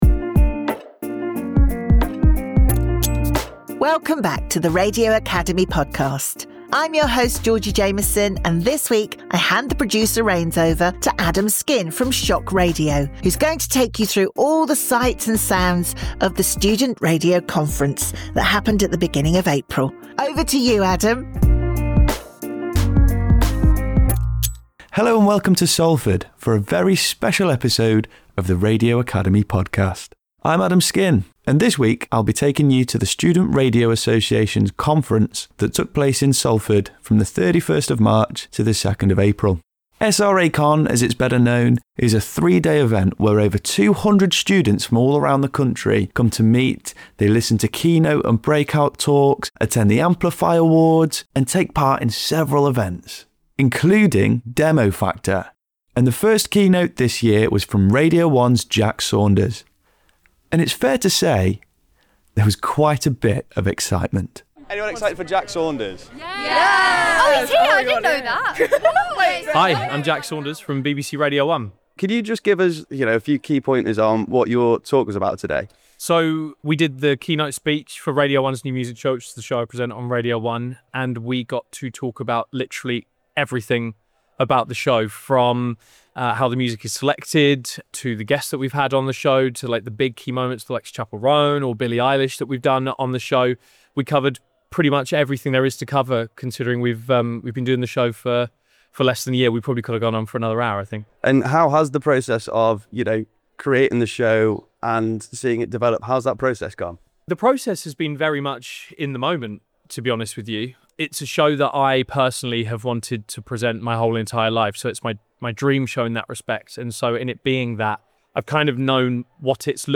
This week's podcast comes from the Student Radio Association Conference hosted at Salford University.